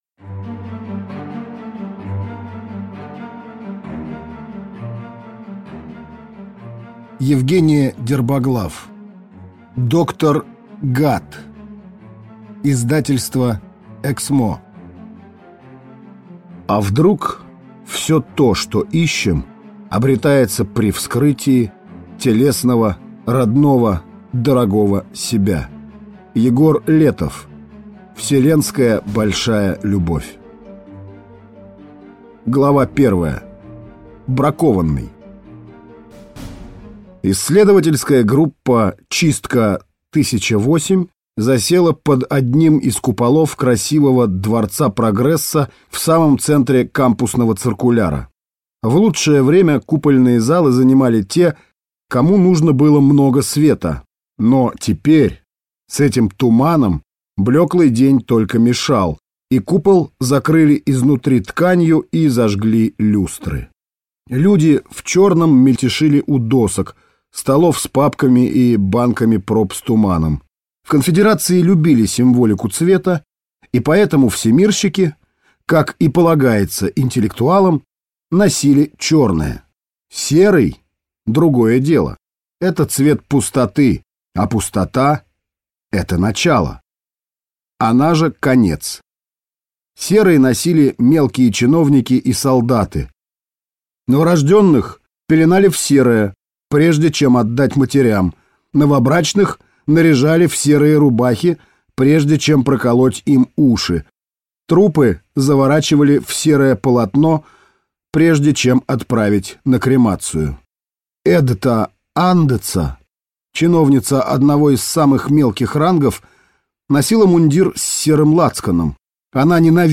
Аудиокнига Доктор гад | Библиотека аудиокниг